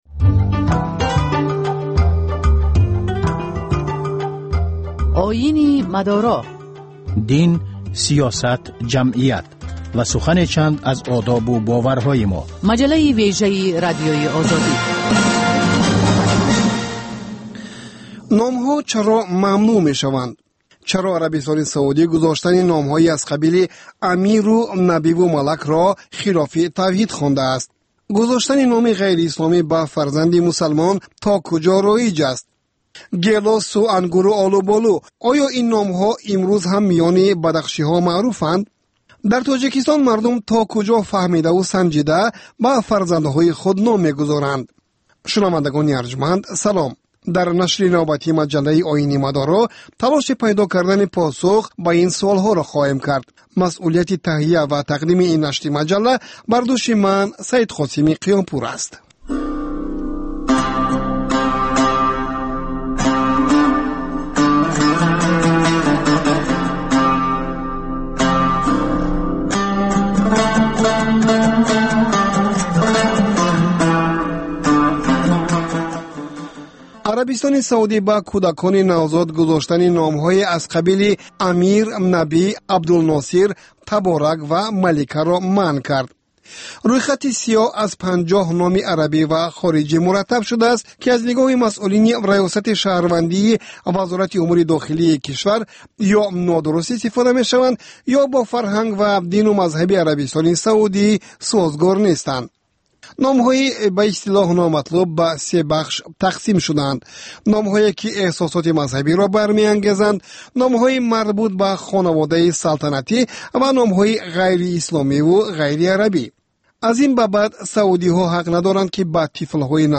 Дин ва ҷомеа. Гузориш, мусоҳиба, сӯҳбатҳои мизи гирд дар бораи муносибати давлат ва дин.